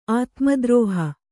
♪ ātmadrōha